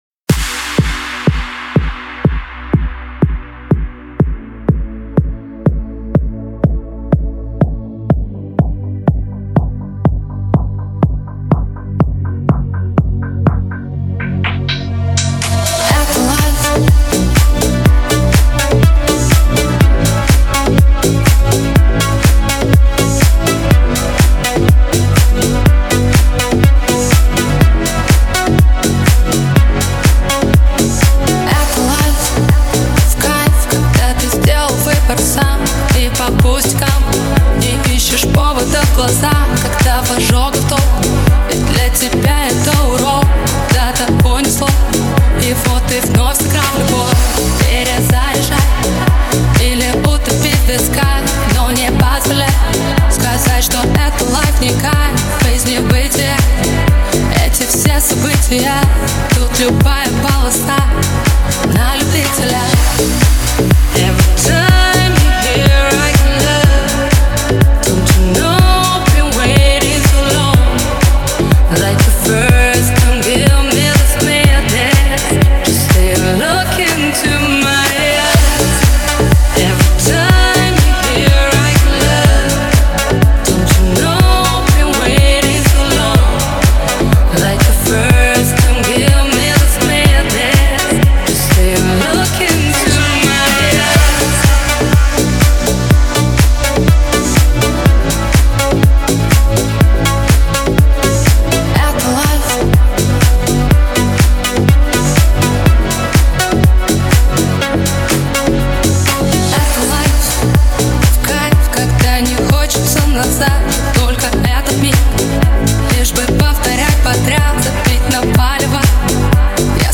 обладая мощным вокалом